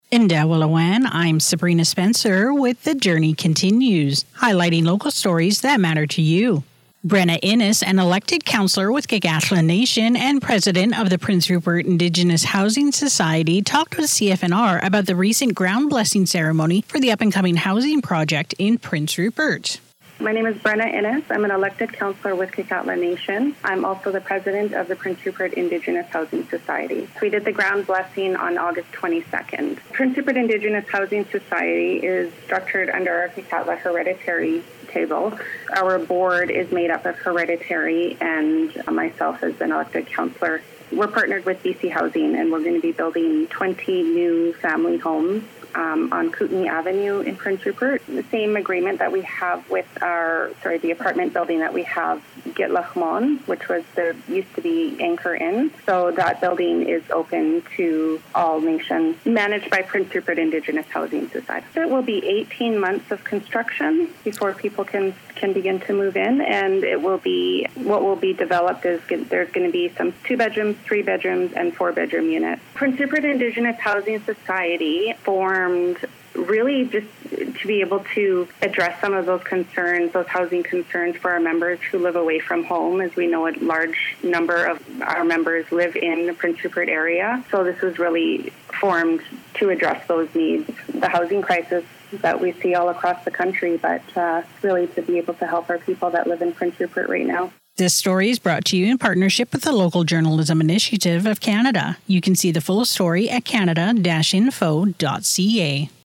Listen to the CFNR interview